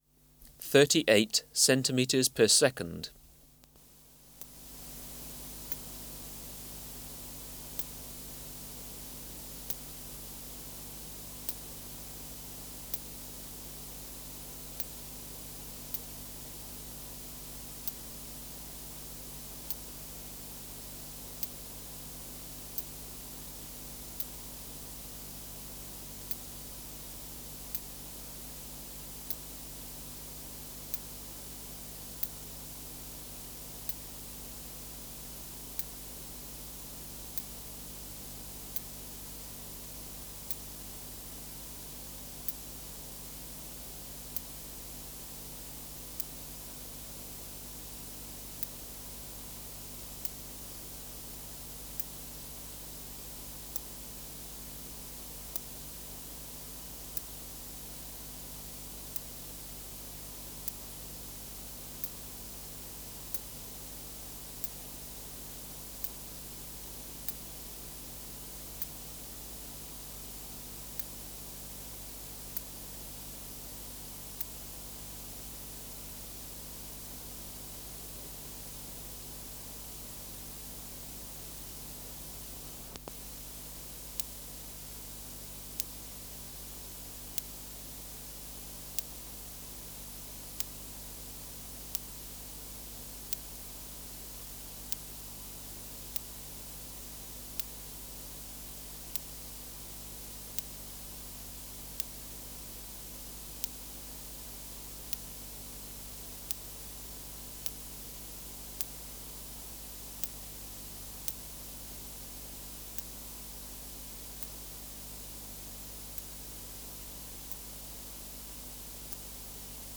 Recording Location: BMNH Acoustic Laboratory
Reference Signal: 1 kHz for 10 s
Substrate/Cage: Large recording cage
Microphone & Power Supply: Sennheiser MKH 405 Filter: Low pass, 24 dB per octave, corner frequency 20 kHz